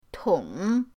tong3.mp3